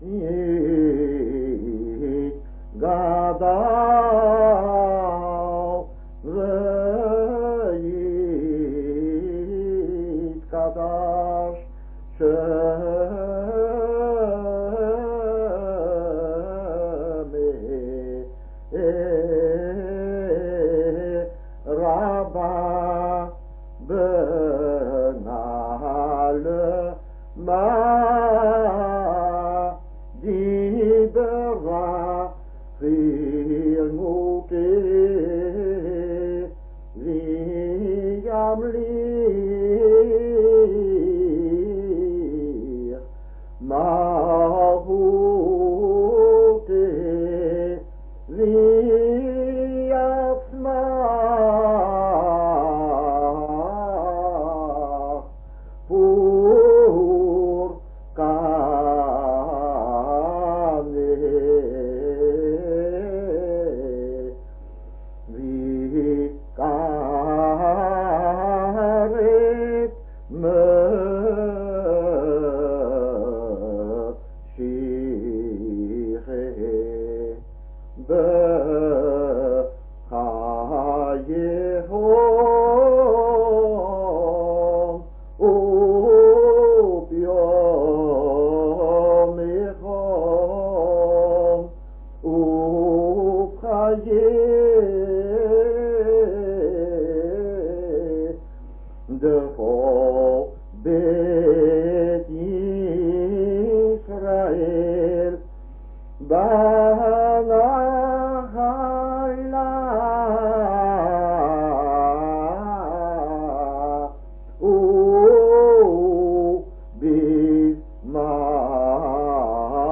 Mind that most recordings were made by non professional Chazzanim and thus are not 100% according to the sheet music.
Simchat Tora morning